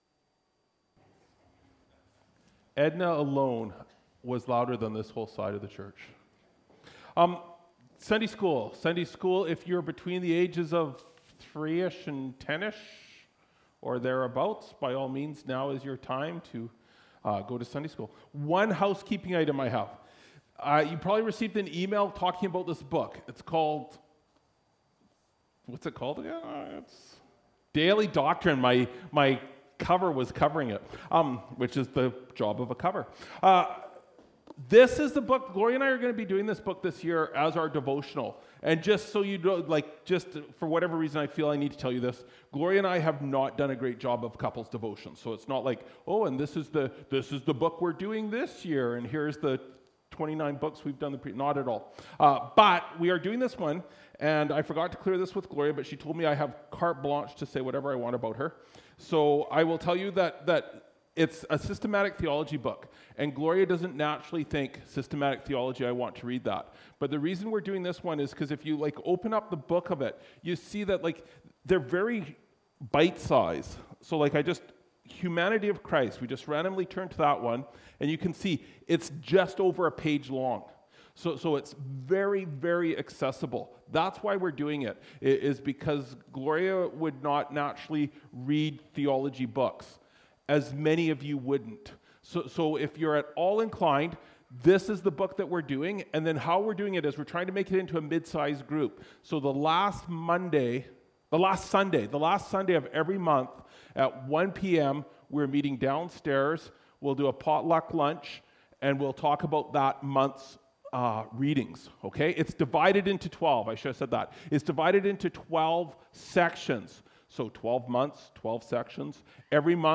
Jan 05, 2025 In the Beginning…God (Genesis 1:1) MP3 SUBSCRIBE on iTunes(Podcast) Notes Discussion Sermons in this Series This sermon was recorded in Salmon Arm and preached in both campuses.